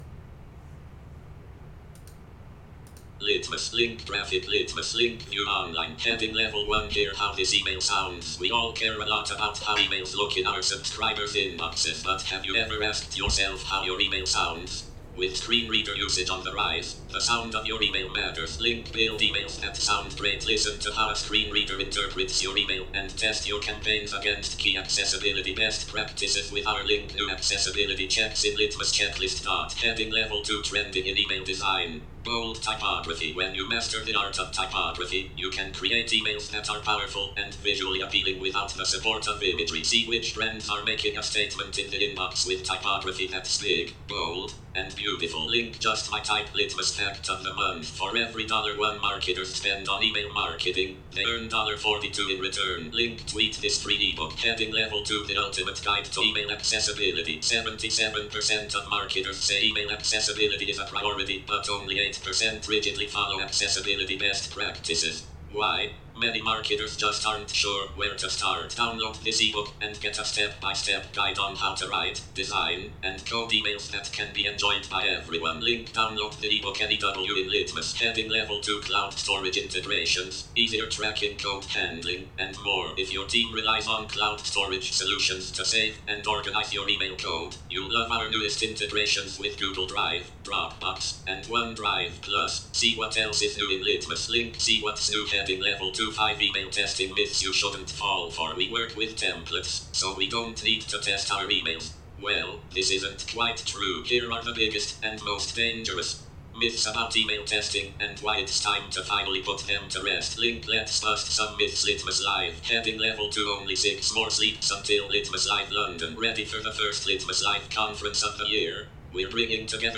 Sample a screen reader recording of the Litmus newsletter
Listen to a screen reader recording of this newsletter.
litmus-newsletter-screen-reader-recording.m4a